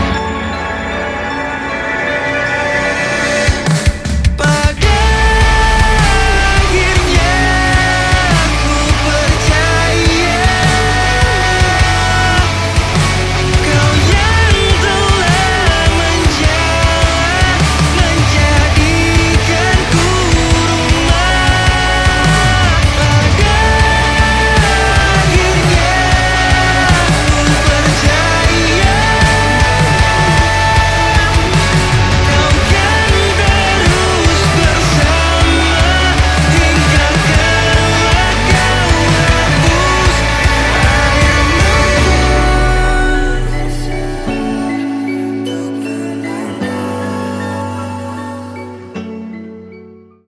Band Alternatif- rock/emo